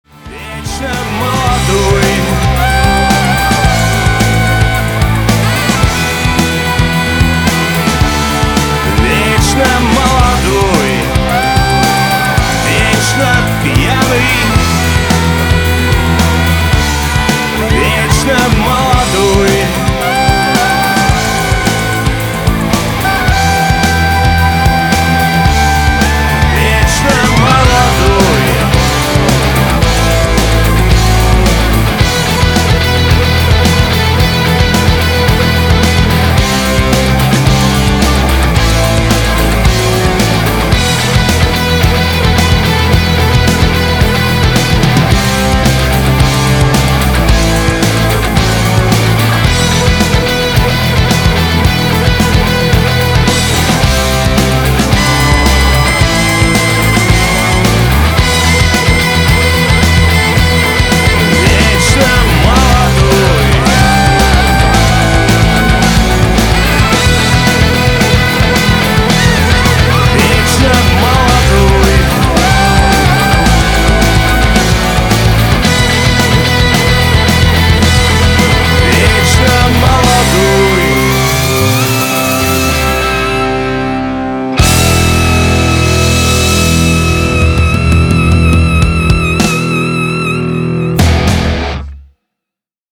душевные
Alternative Rock
Саксофон
воодушевляющие
post-punk